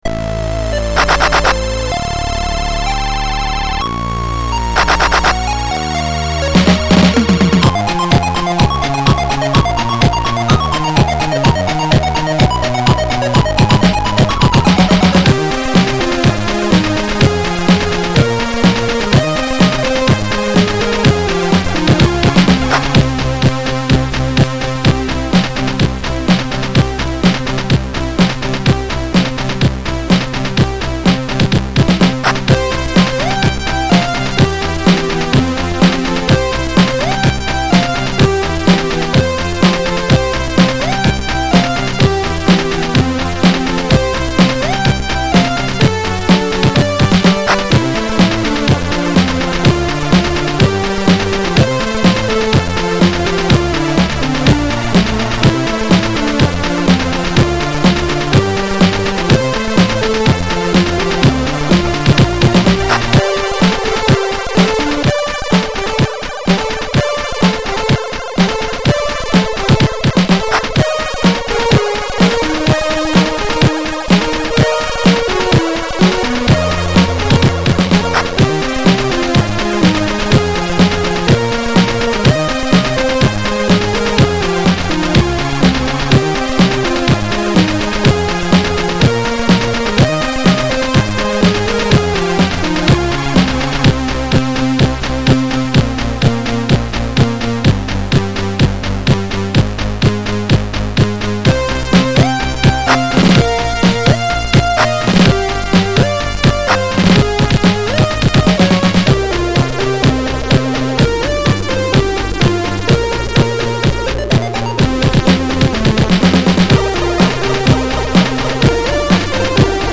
Music, as played by SIDMan
Has digi percussion.